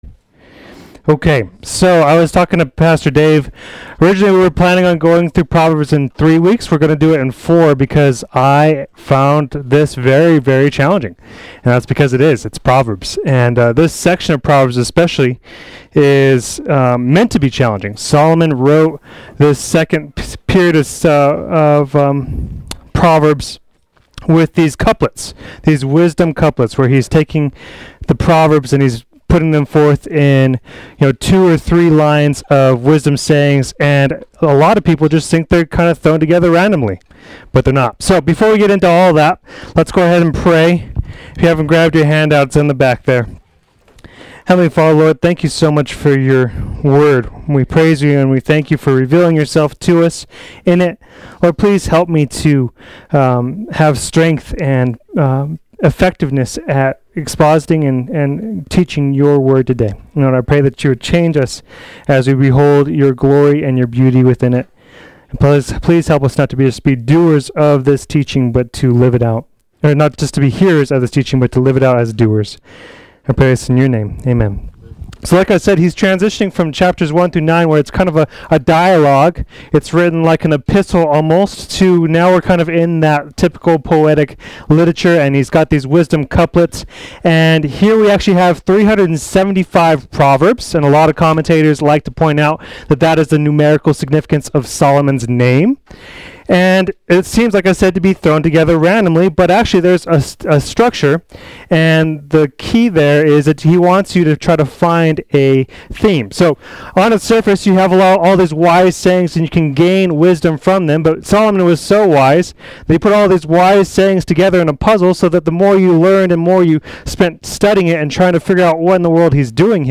Play Sermon Get HCF Teaching Automatically.
Part 2 Adult Sunday School